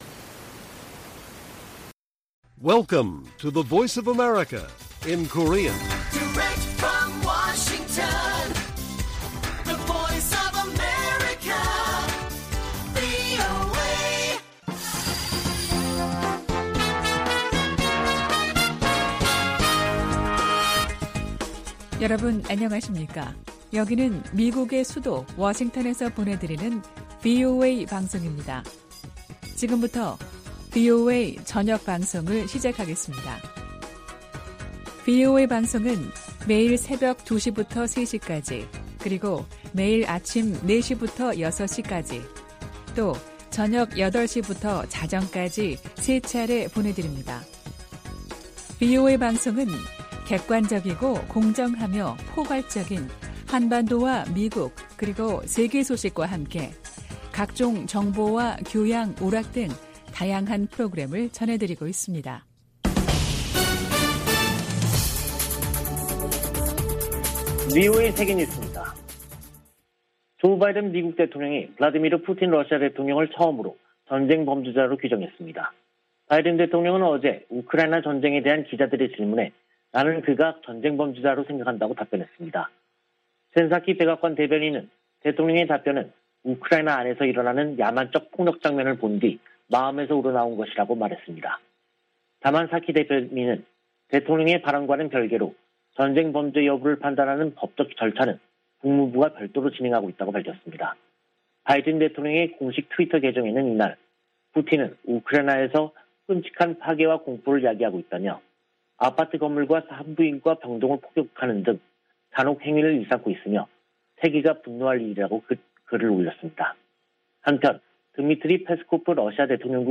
VOA 한국어 간판 뉴스 프로그램 '뉴스 투데이', 2022년 3월 17일 1부 방송입니다. 미군 당국은 한반도의 어떤 위기에도 대응 준비가 돼 있다며, 억지력 기초는 준비태세라고 강조했습니다. 북한은 16일 탄도미사일 발사 실패에 침묵하고 있습니다. 미국의 전문가들은 북한의 지속적인 미사일 발사에 미한이 억지력 강화에 주력하면서 한반도 긴장이 고조될 것이라고 관측했습니다.